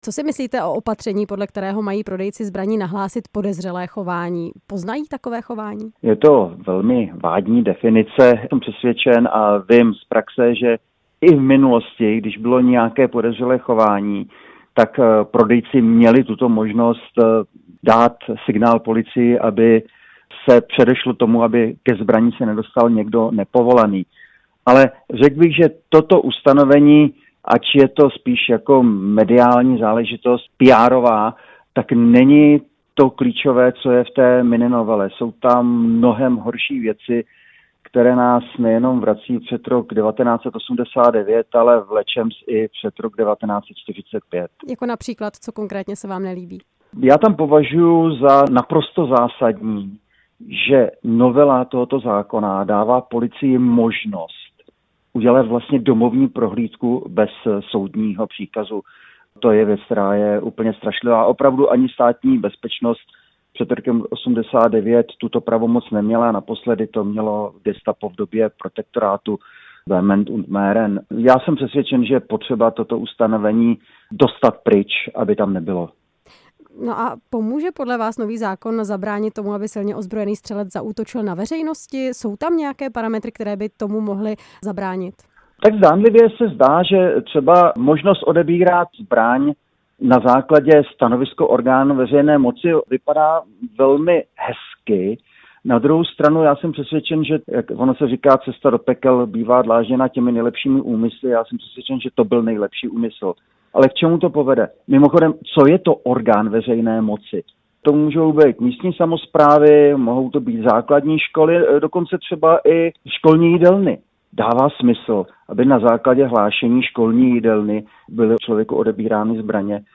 Podle čerstvé novely zákona o zbraních mají prodejci policii oznámit převody zbraně nebo střeliva, které důvodně považují za podezřelé. Více k tématu řekl host vysílání Radia Prostor Jiří Hynek, prezident Asociace obranného a bezpečnostního průmyslu.